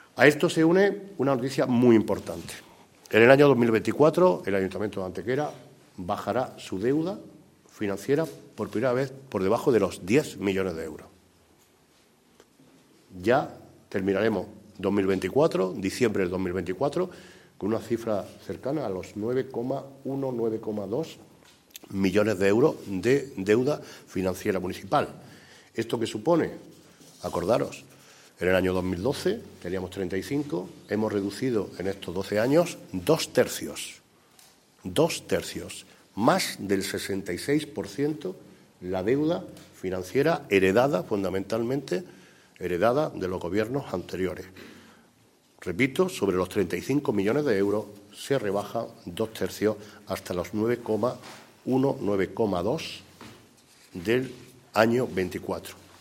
El alcalde de Antequera, Manolo Barón, ha presentado hoy ante los medios de comunicación las líneas maestras de los Presupuestos Generales del Ayuntamiento de Antequera para el ejercicio económico 2024, en una rueda de prensa junto al teniente de alcalde delegado de Hacienda, Antonio García Acedo y el resto de miembros del Equipo de Gobierno del Ayuntamiento de Antequera.
Cortes de voz